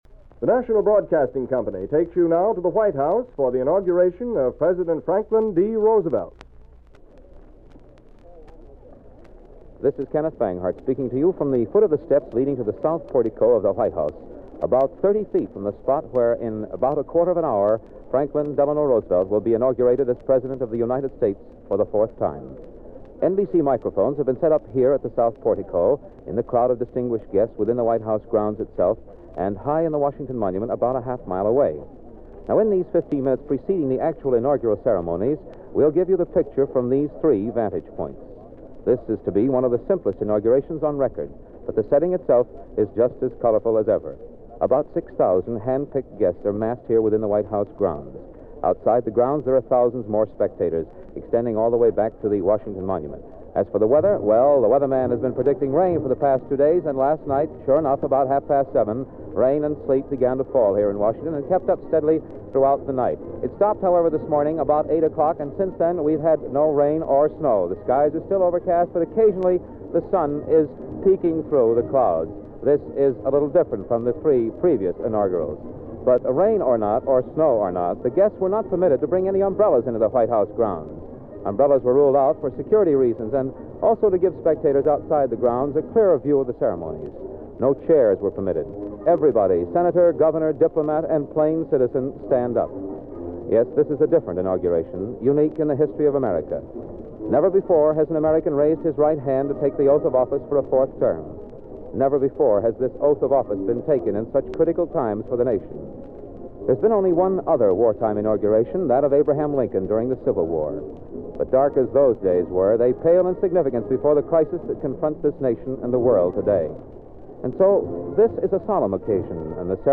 1945 Inauguration Broadcast
1945-FDR-Inaugural-Broadcast-Audio.mp3